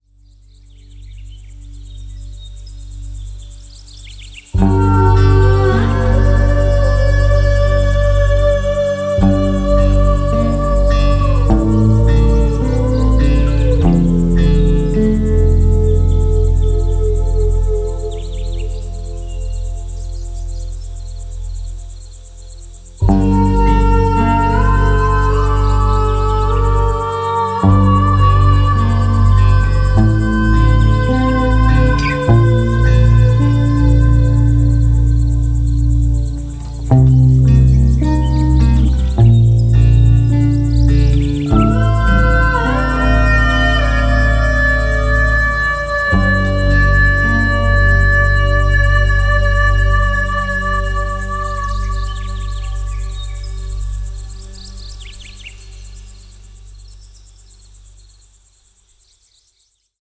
Schumann-Frequenz (432 Hz)
• Format: Musik